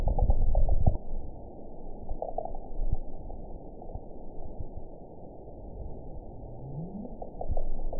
event 921901 date 12/21/24 time 13:09:08 GMT (11 months, 2 weeks ago) score 8.73 location TSS-AB03 detected by nrw target species NRW annotations +NRW Spectrogram: Frequency (kHz) vs. Time (s) audio not available .wav